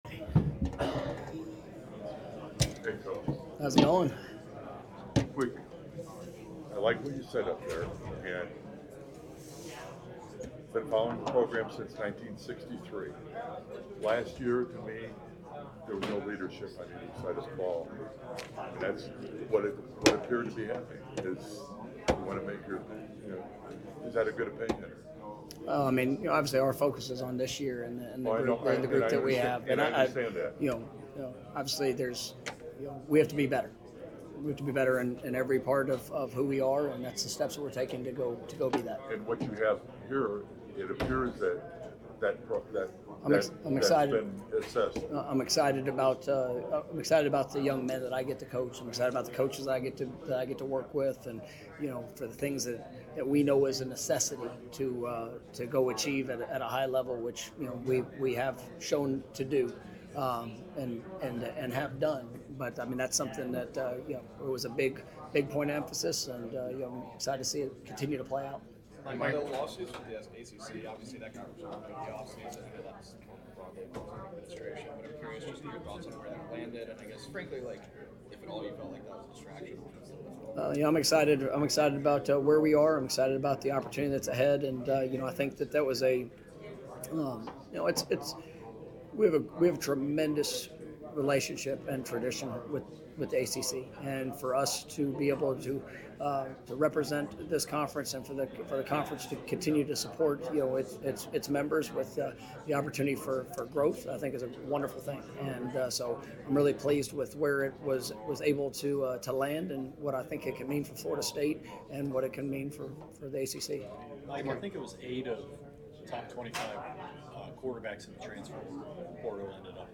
Mike Norvell Breakout Session Interview from ACC Kickoff
Hear everything FSU head coach Mike Norvell had to say during his breakout session with media members at the ACC Football Kickoff 2025.